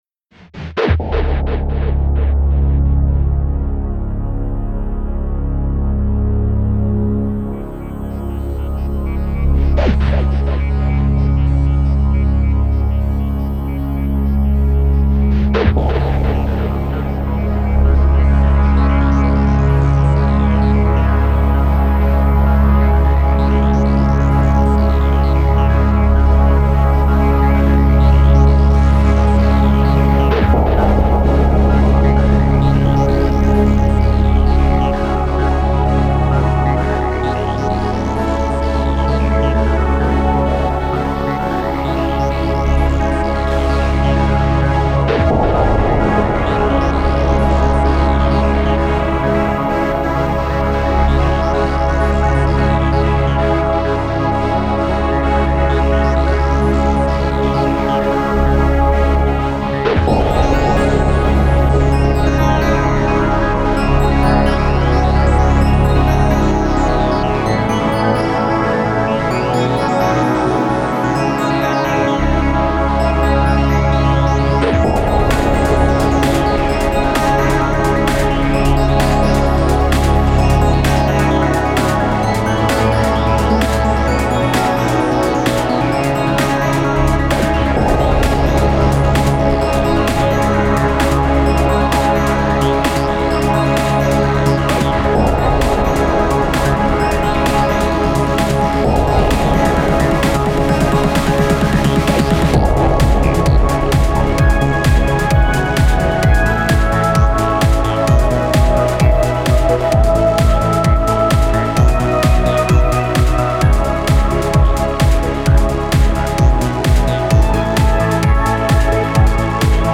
This is a remix